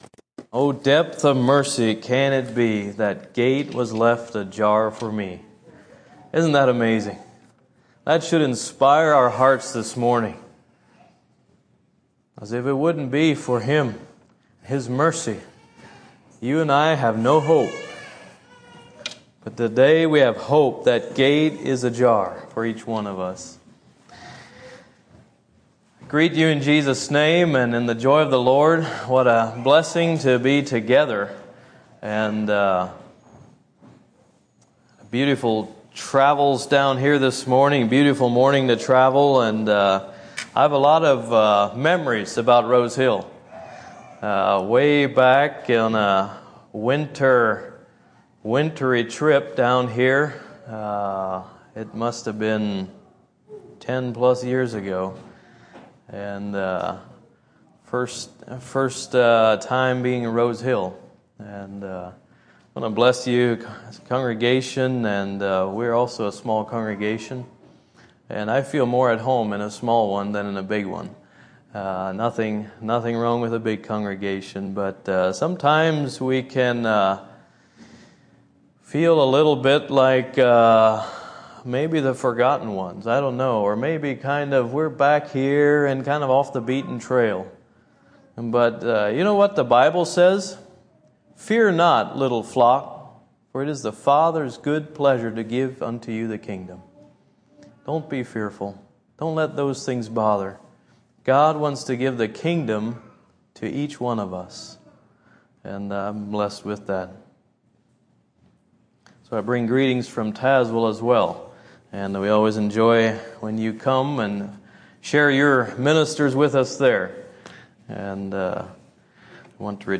A Sermon By